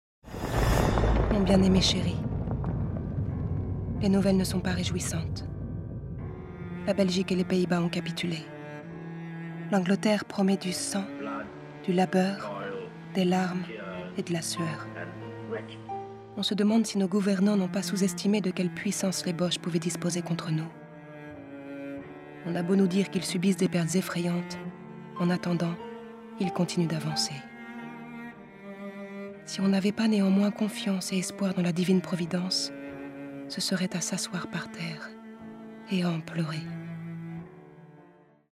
Voix off, Ma vie dessinée de V. Pouchain